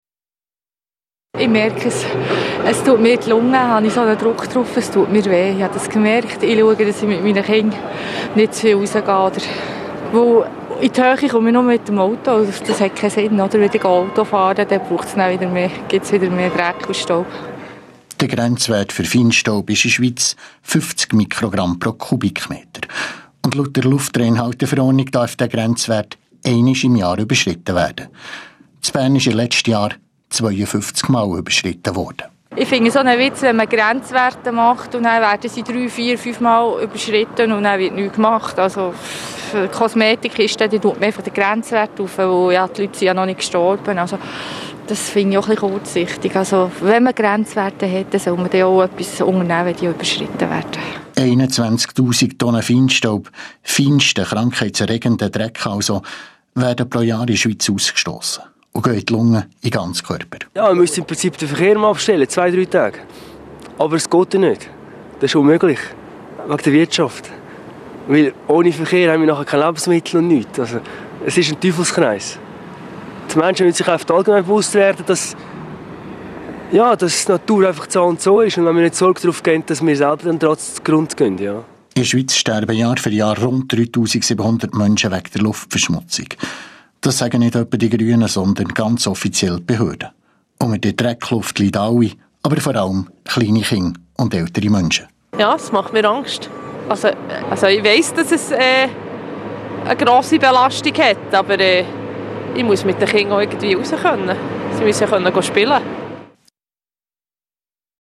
Im Januar litt das Schweizer Mittelland für viele Tage unter einer giftigen Dreckluft. Der Grenzwert für Feinstaub – Luftschadstoffe aus Motoren und Heizungen – wurde ständig überschritten. Reaktionen aus Bern, wo das Dreifache der maximal zulässigen Menge gemessen wurde.